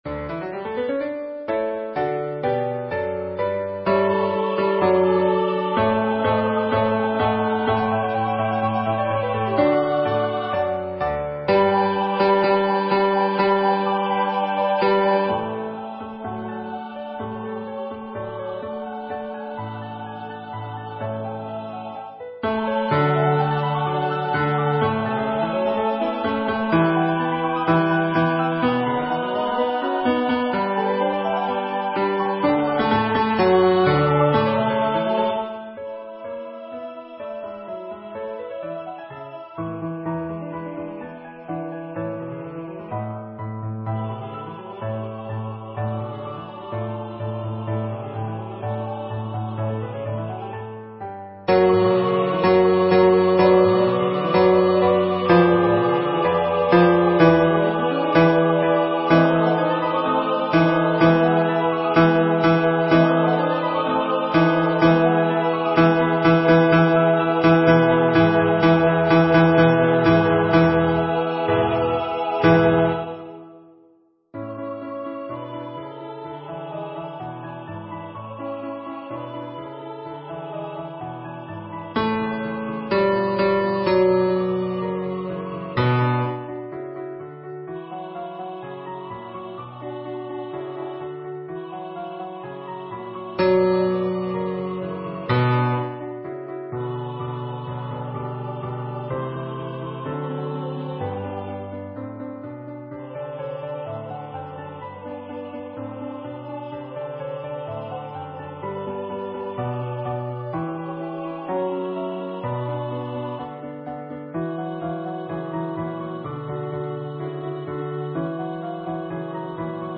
Practice Files: Soprano:     Alto:     Tenor:     Bass:
Number of voices: 4vv   Voicing: SATB
Genre: SacredMass
Instruments: Piano